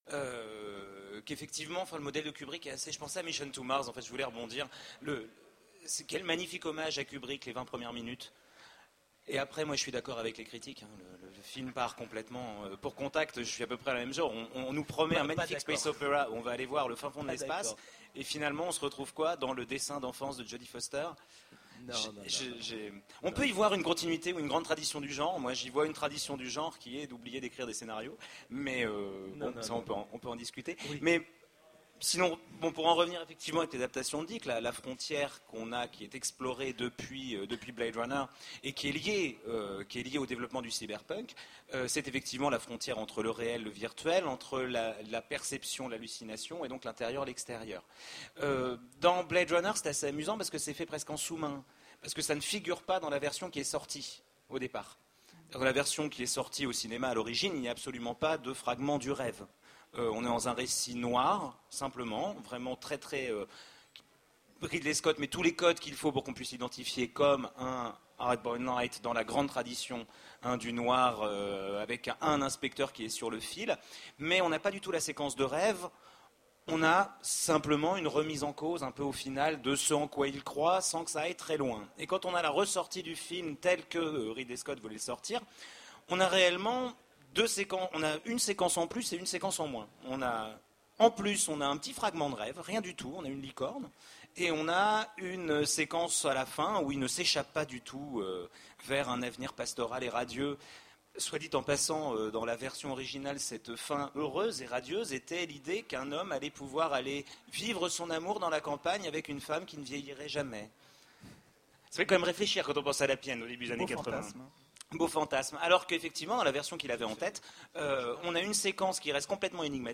Utopiales 2010 : Conférence Frontières et passage dans le cinéma de SF